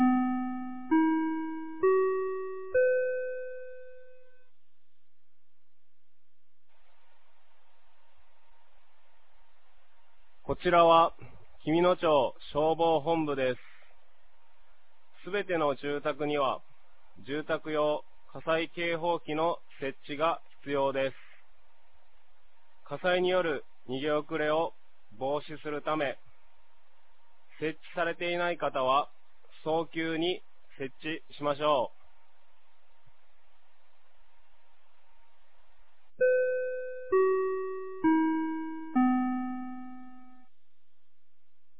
2025年12月20日 16時00分に、紀美野町より全地区へ放送がありました。